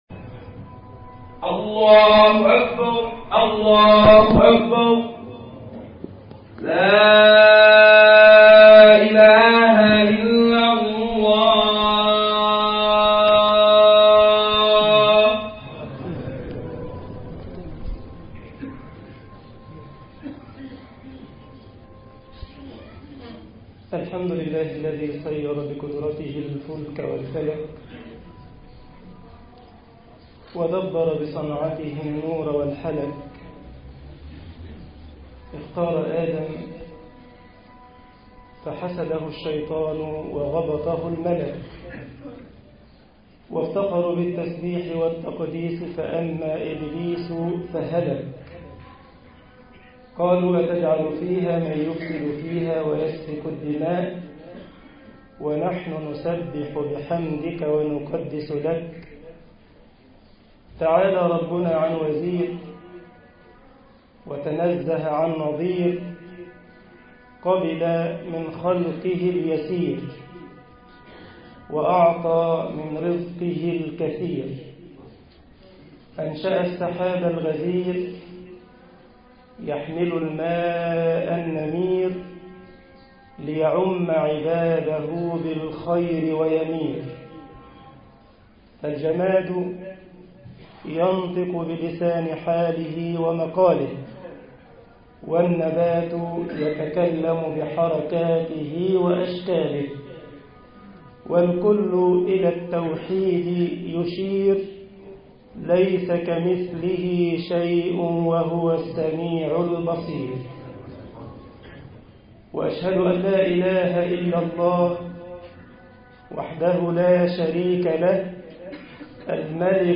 البلايا على مقادير الرجال طباعة البريد الإلكتروني التفاصيل كتب بواسطة: admin المجموعة: مواضيع مختلفة Download مصر خطبة جمعة 13 يناير 2012 م التفاصيل نشر بتاريخ: الأربعاء، 07 آذار/مارس 2012 17:35 الزيارات: 2643 السابق التالي